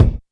WU_BD_046.wav